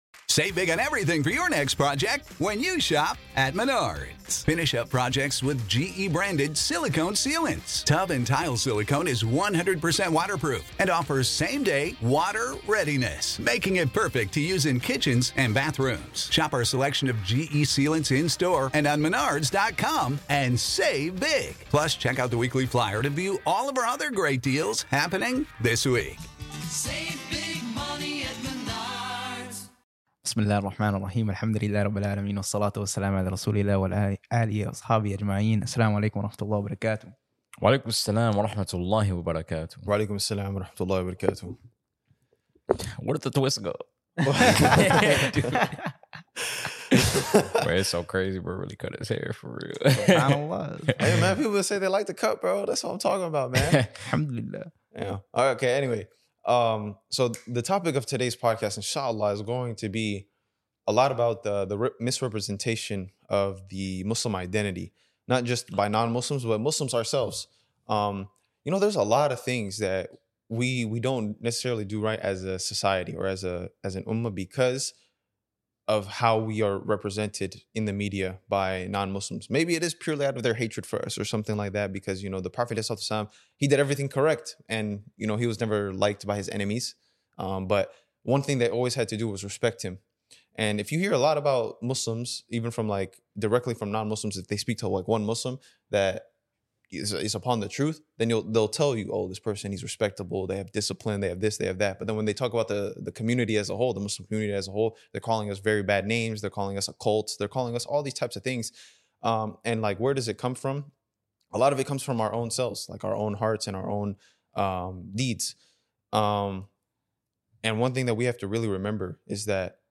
DeenTour is a podcast and channel where 3 brothers showcase their love for islam through reminders, brotherhood, motivation, entertainment, and more!